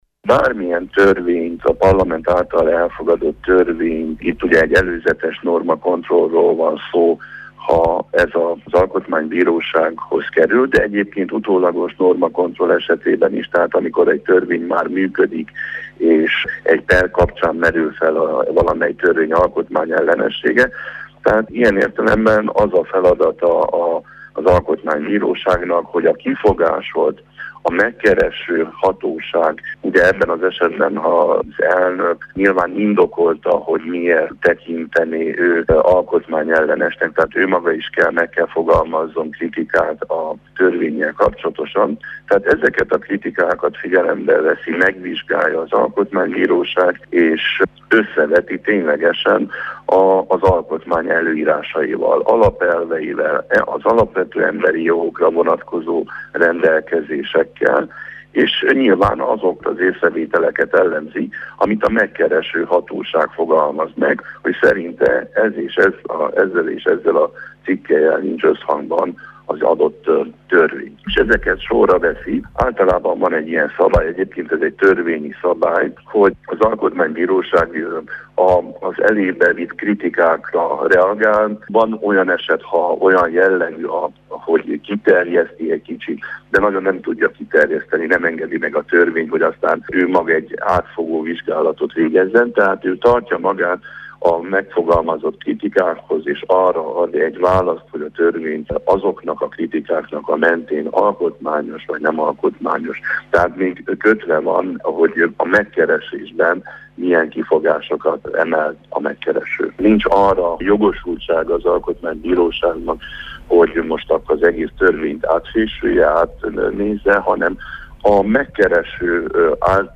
Varga-Attila-interju.mp3